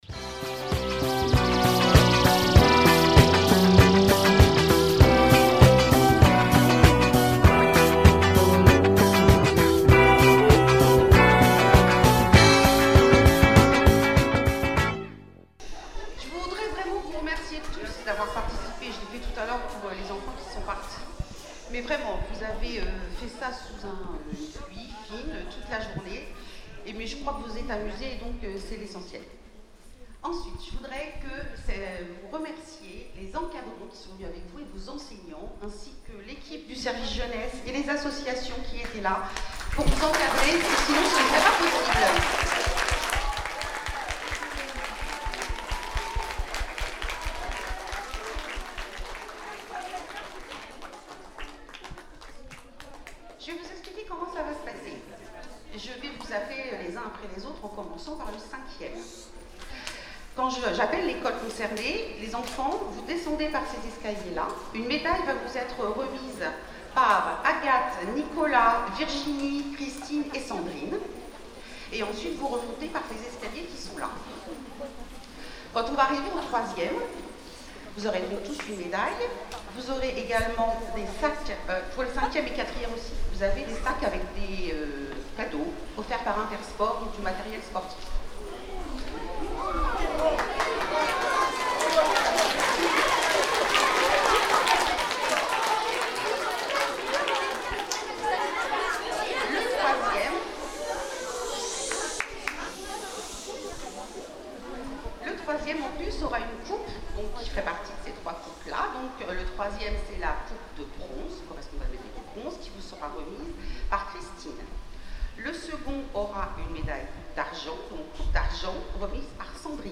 Olympiades des écoles 2025 - Cérémonie de clôture du 11.06.2025